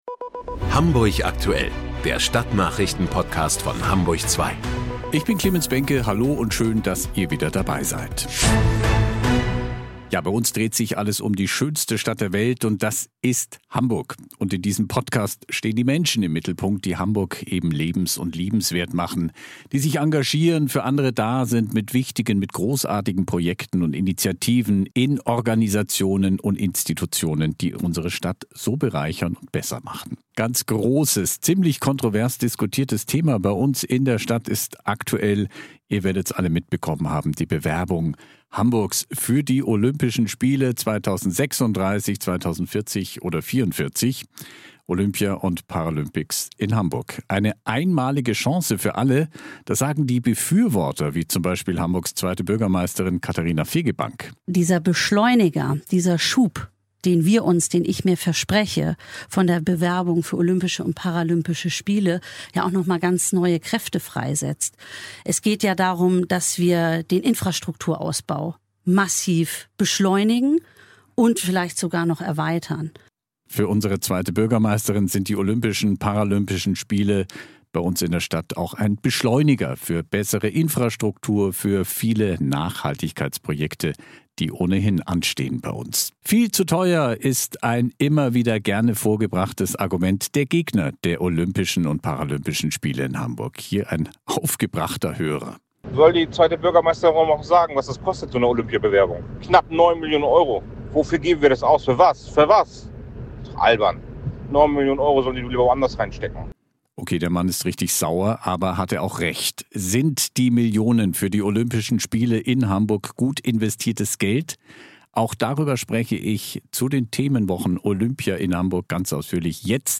Olympia in Hamburg - Katharina Fegebank im Talk ~ HAMBURG AKTUELL - Der Stadtnachrichten Podcast von Radio Hamburg und HAMBURG ZWEI Podcast